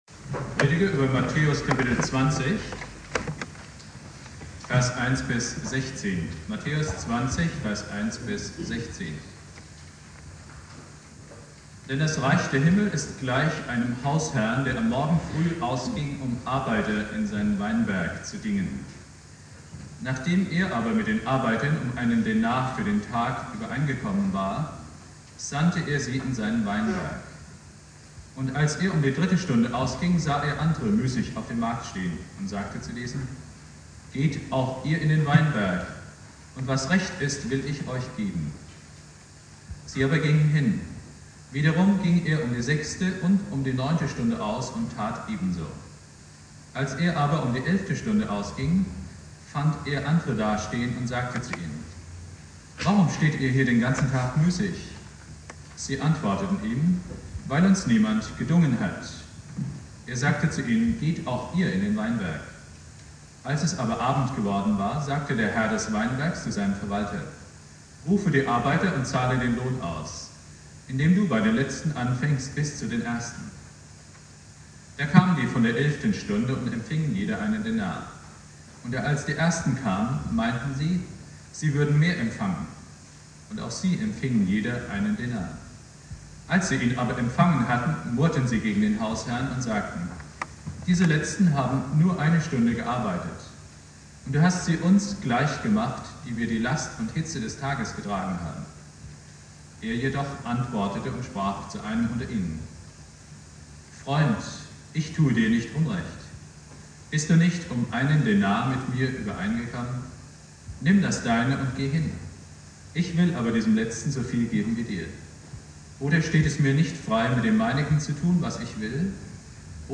Predigt
"Die Arbeiter im Weinberg" (mit Außenmikrofon aufgenommen) Bibeltext